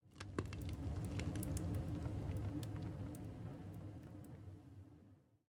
blastfurnace2.ogg